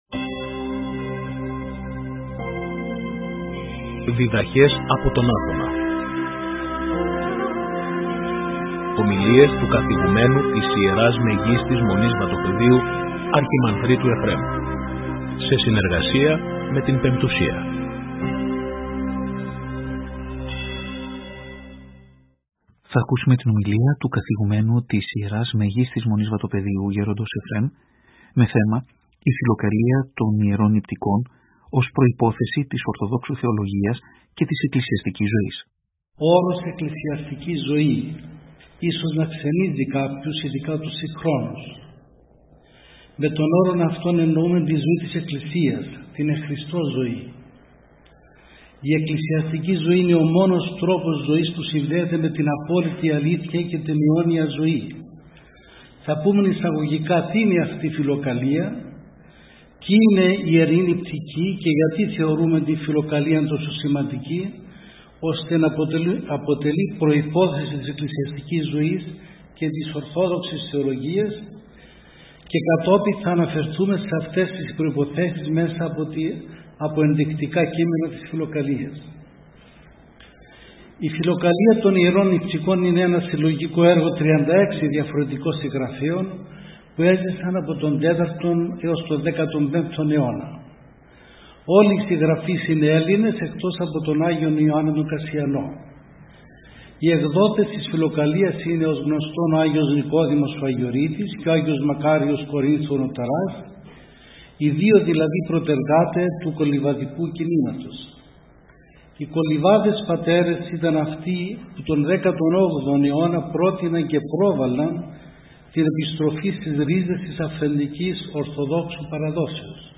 Ομιλία
Η ομιλία μεταδόθηκε και από την εκπομπή «Διδαχές από τον Άθωνα» στη συχνότητα του Ραδιοφωνικού Σταθμού της Πειραϊκής Εκκλησίας την Κυριακή 30 Μαρτίου 2025.